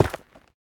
Minecraft Version Minecraft Version latest Latest Release | Latest Snapshot latest / assets / minecraft / sounds / block / netherrack / break1.ogg Compare With Compare With Latest Release | Latest Snapshot